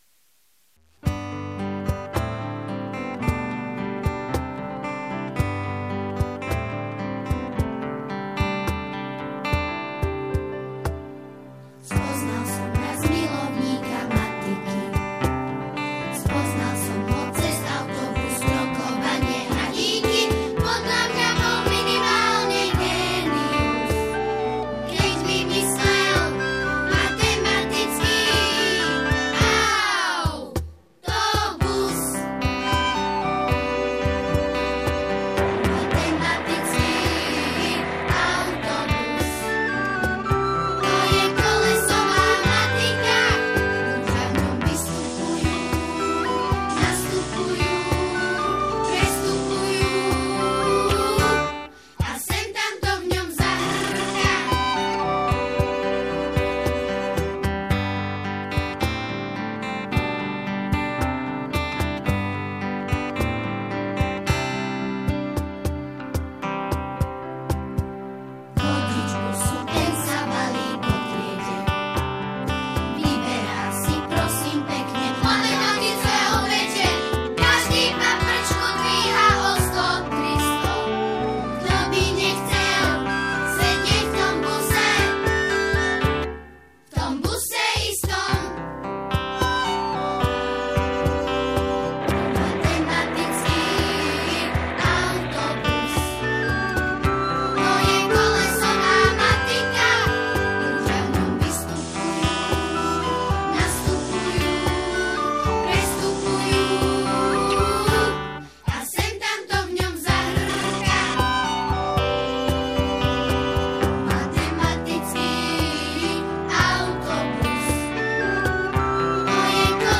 Zároveň si pesničky môžete stiahnuť ako mp3, niektoré aj v dvoch verziách, buď len samostatnú melódiu, alebo aj so spevom.
Matematický autobus (Text, hudba: Bibiana Kľačková, Spev: žiaci SZŠ Dotyk Ružomberok)